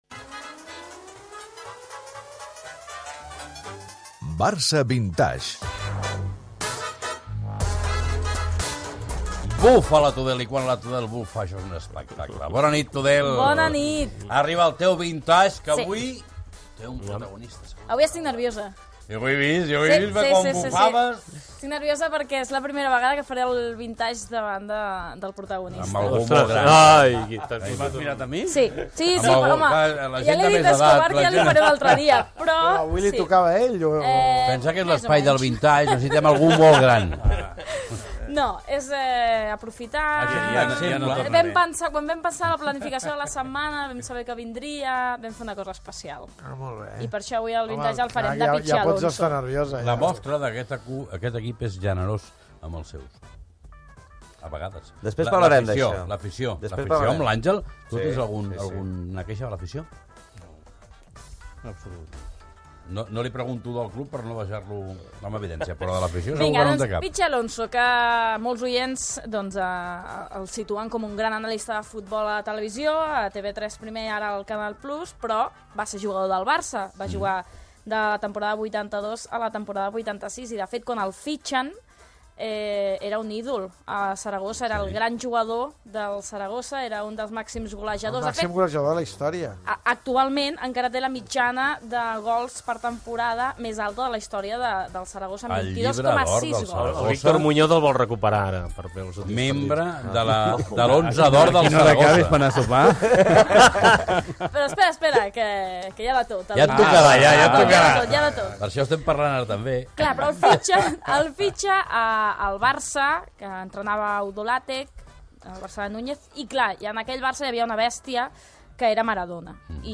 amb declaracions de Víctor Muñoz Gènere radiofònic Esportiu